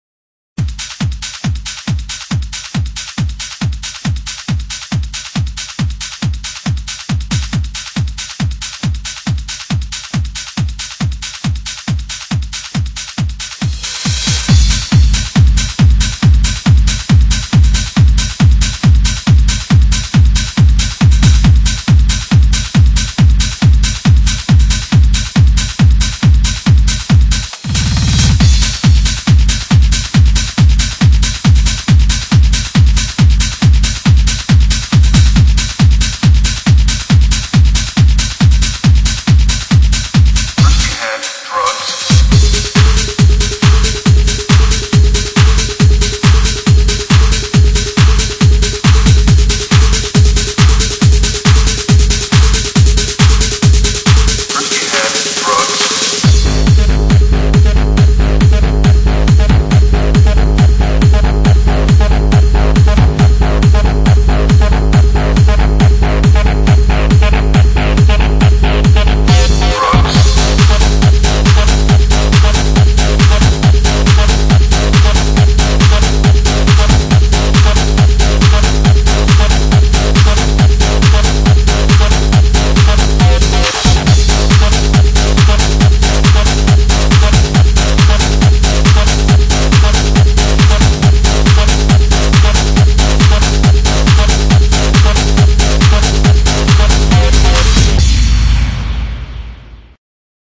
BPM138--1
Audio QualityPerfect (High Quality)